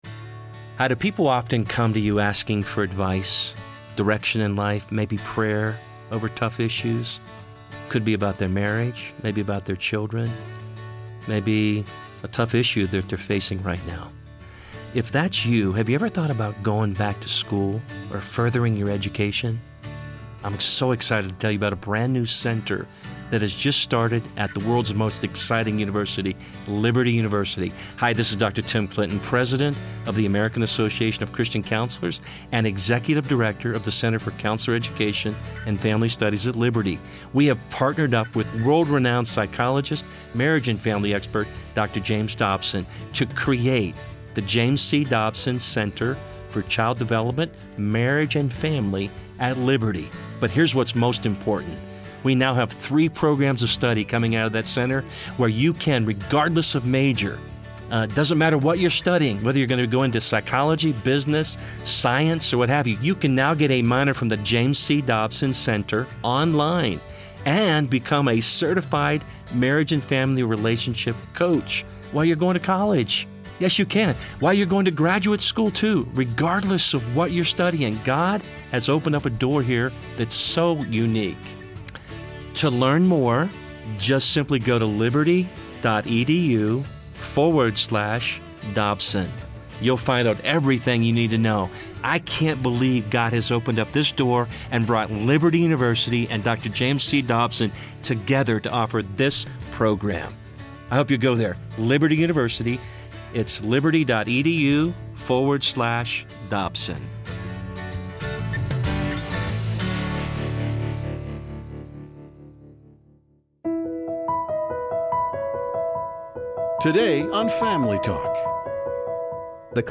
American culture in this day and age has become more godless and accepting of evil. the late Pastor Adrian Rogers preaches through Psalm 80 and explains the dangers of Americas continuing rebellion against God.